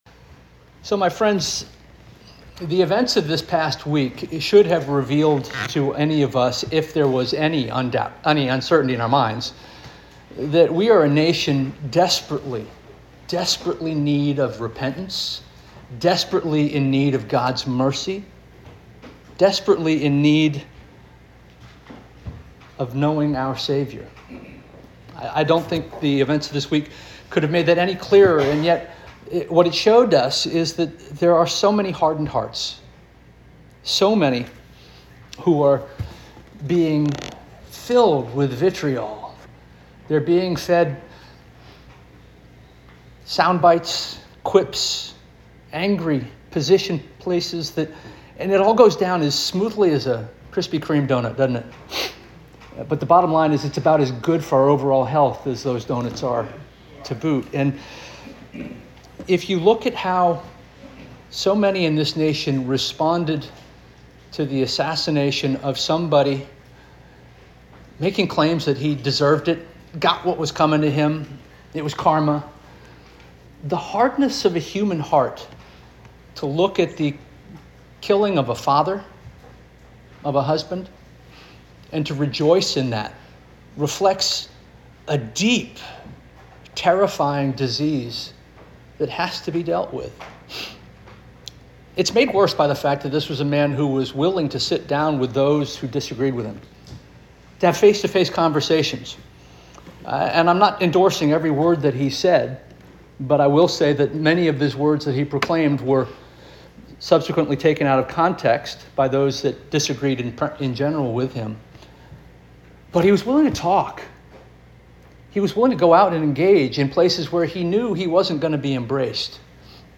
September 14 2025 Sermon - First Union African Baptist Church